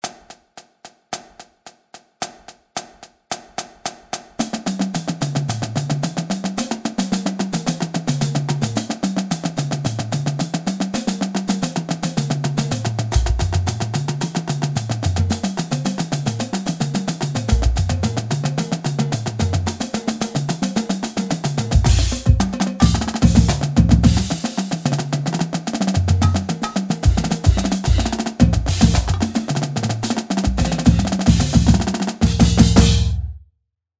Stick Control hereta - 110.mp3